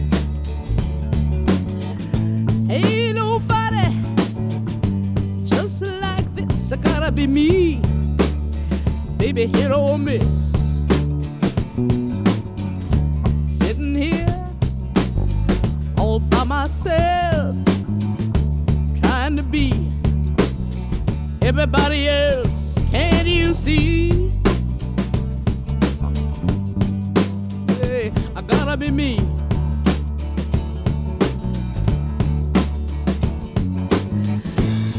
# Blues